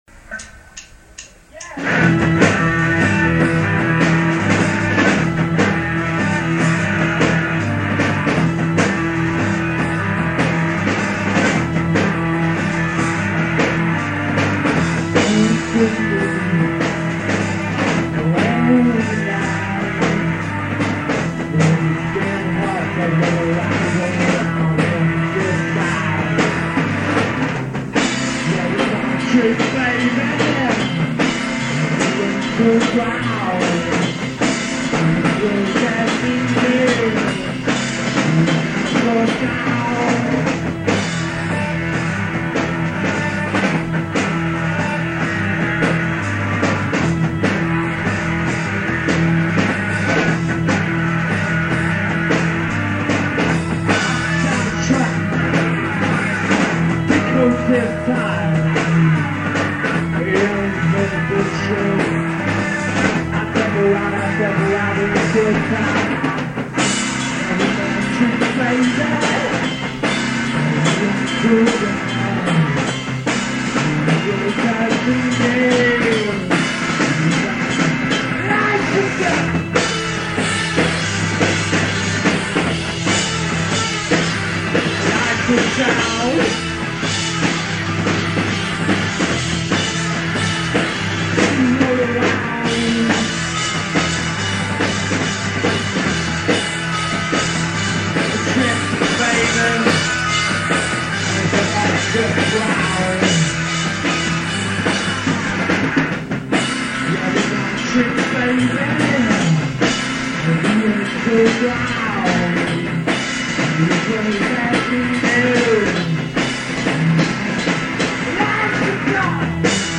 bass
guitar